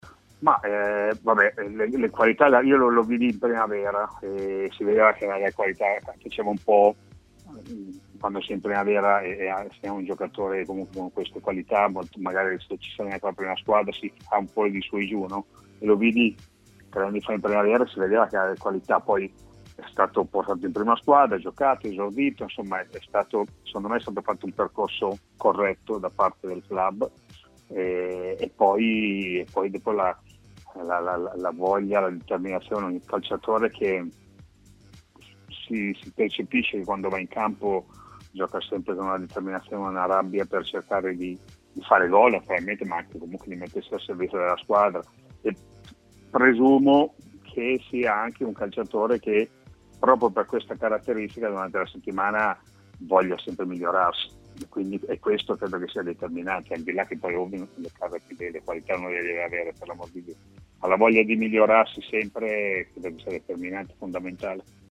Intervistato da TMW